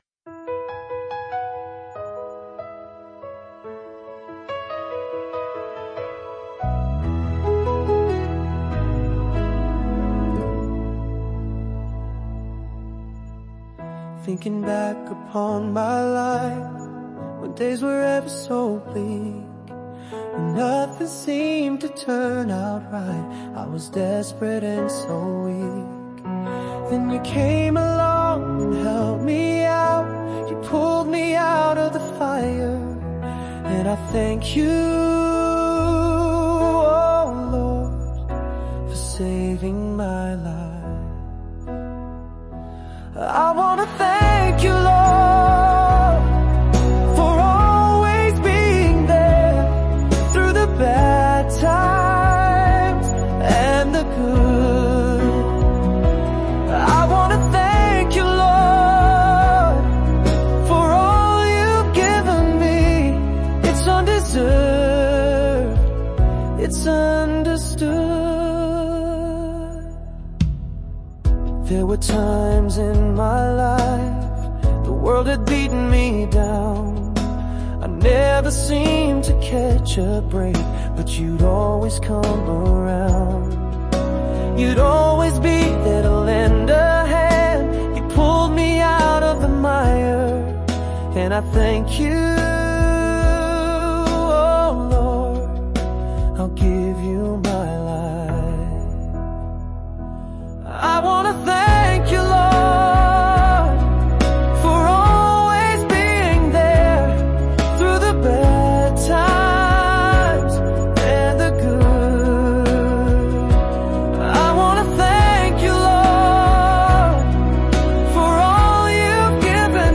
Finished with music and vocal July17,2025